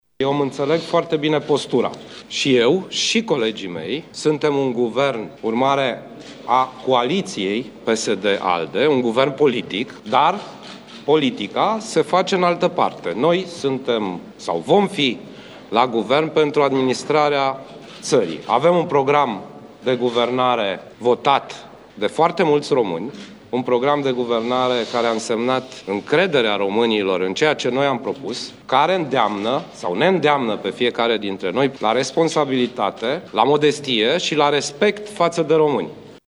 Premierul desemnat, Sorin Grindeanu, a ținut să menționeze că acesta va fi un Guvern politic, urmare a coaliției majoritate, care va pune în prim-plan interesul românilor: